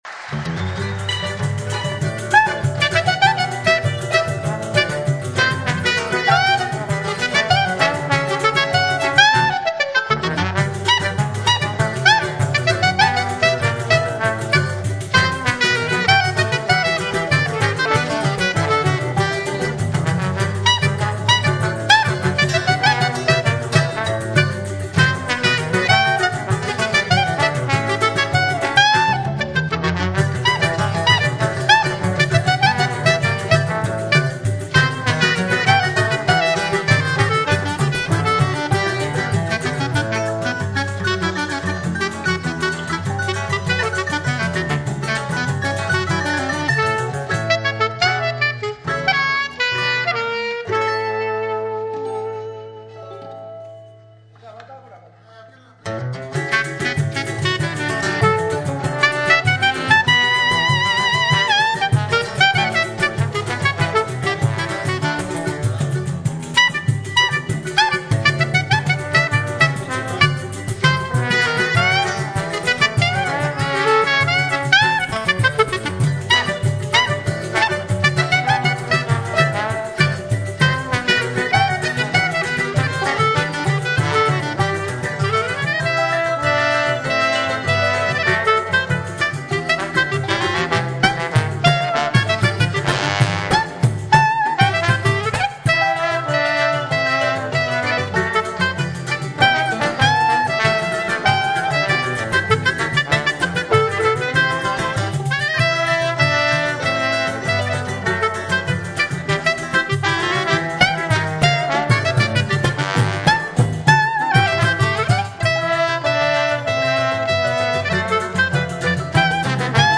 gravado ao vivo em Barra Mansa, RJ, 1997
Gênero: Choro